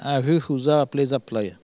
Elle crie pour appeler les oies
Locution